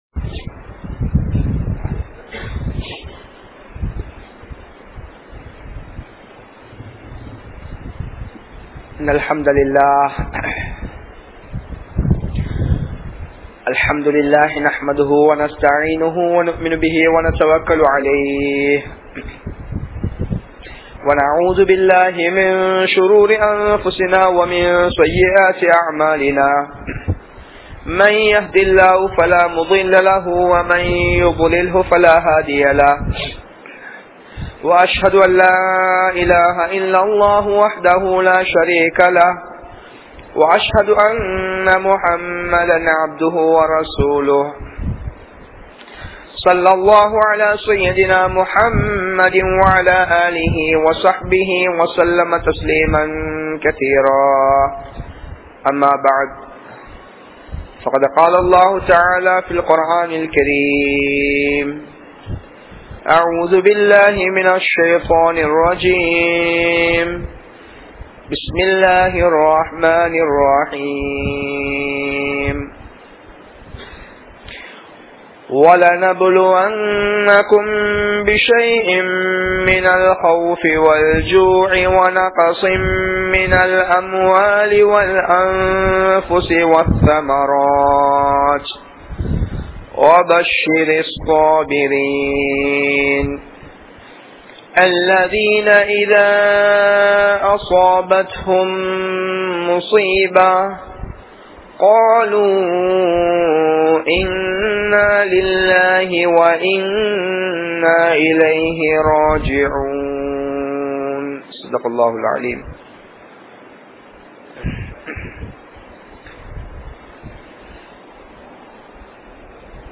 Soathanaien Mudivuhal (சோதனையின் முடிவுகள்) | Audio Bayans | All Ceylon Muslim Youth Community | Addalaichenai
Kanampittya Masjithun Noor Jumua Masjith